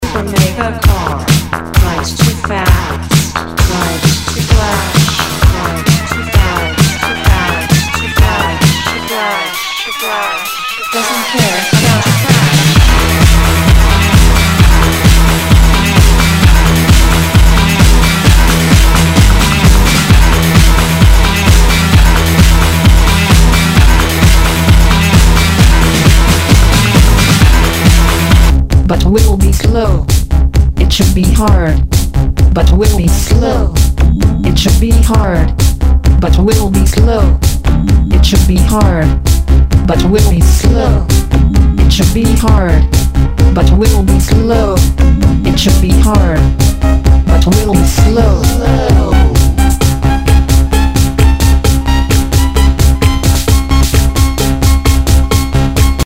HOUSE/TECHNO/ELECTRO
ナイス！エレクトロ・テクノ！